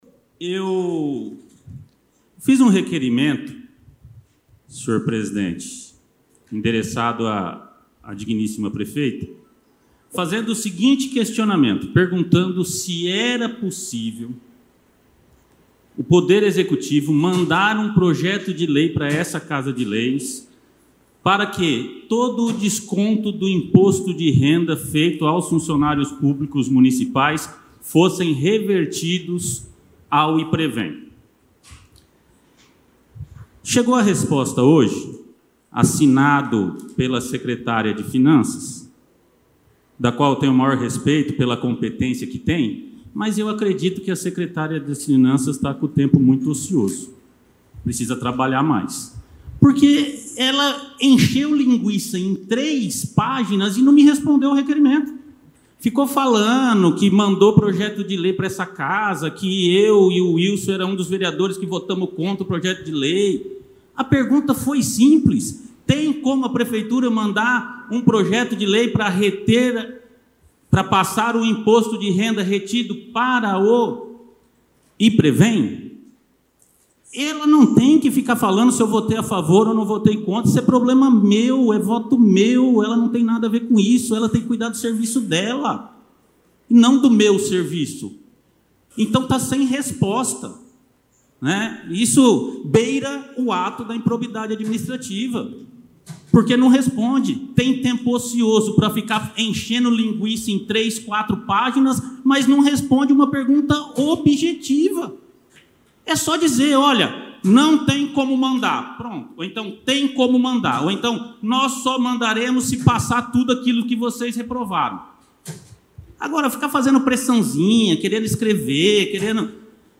Na noite de ontem (11), na sessão ordinária da Câmara Municipal de Presidente Venceslau, o vereador Tácito Alexandre expressou toda a sua insatisfação com a resposta da Secretária de Finanças da Prefeitura Municipal a um de seus requerimentos.
Ouça na íntegra o pronunciamento do vereador:
TACITO-ALEXANDRE-discurso-pesado.mp3